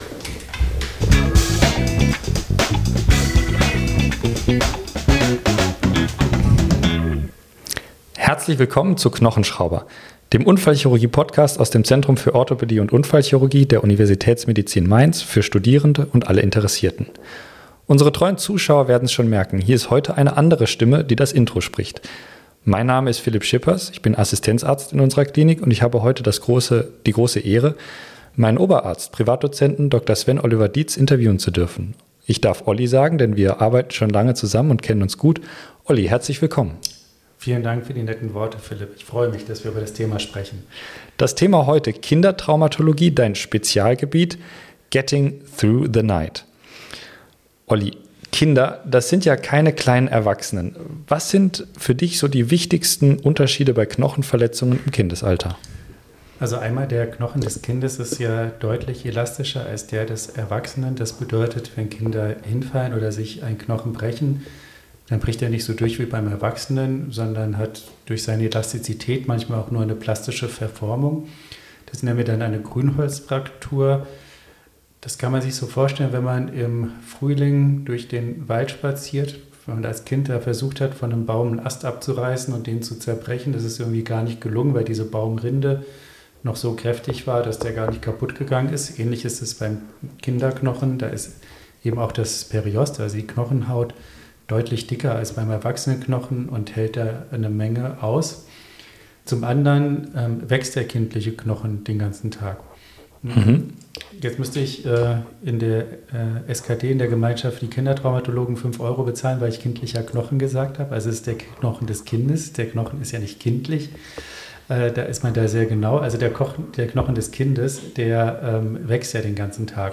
Zusammen mit seinen Interviewgästen nimmt er Euch mit in den klinischen Alltag am ZOU, bespricht mit ihnen praxisnah relevante Krankheitsbilder, diagnostische und therapeutische Prinzipien sowie typische Fallbeispiele – fundiert, verständlich und auf Augenhöhe.